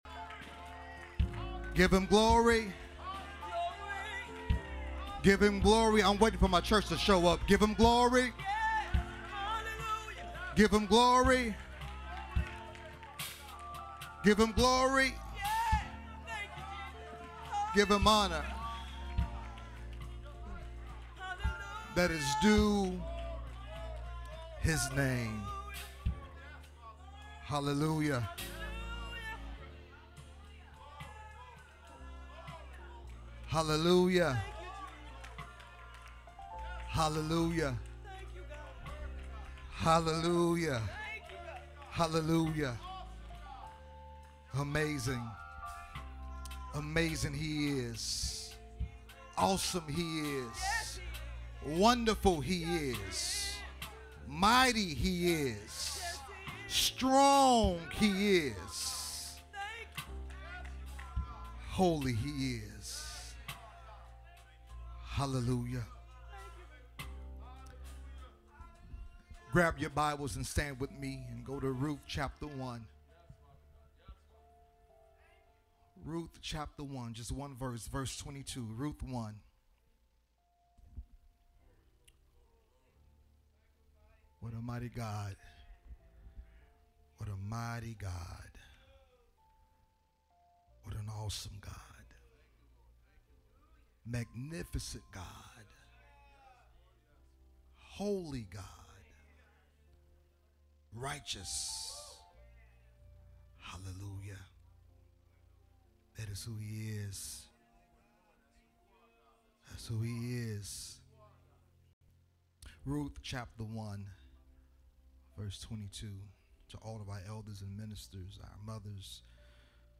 Hopewell Missionary Baptist Church, Carbondale IL
audio sermon